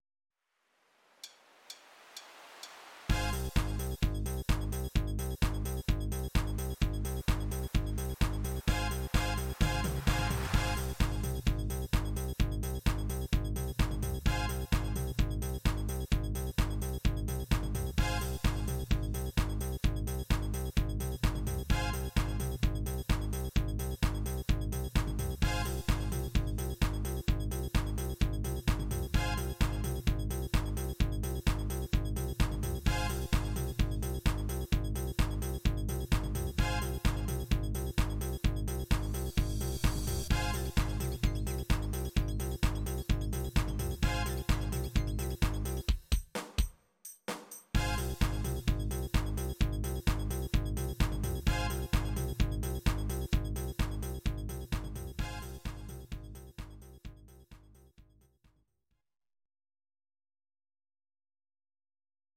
Audio Recordings based on Midi-files
Pop, 1980s